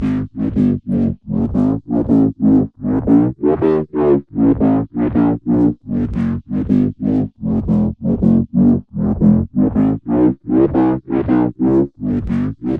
摇摆不定的贝司声 " 贝司重采样4
描述：音乐制作的疯狂低音
标签： 重采样 重低音 音效设计 摇晃
声道立体声